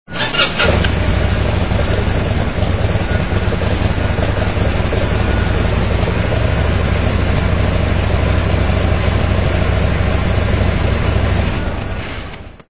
Descarga de Sonidos mp3 Gratis: motor 1.
motor_bike.mp3